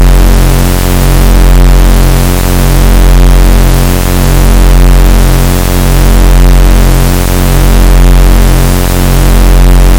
This object is rotating at 37.19 RPM and so this induced buzzing has a warbling “beat frequency” of 37.19 BPM.
GEEK NOTES: The MP3 is a sawtooth wave at 45 Hz and a square wave at 45.62 Hz mixed with both white and brownian noise.
Here’s a simulation I made using audio software of my conception of the sound Gator (and everyone else on Earth) hears.
If you play the MP3 you’ll probably agree it’s exceptionally annoying.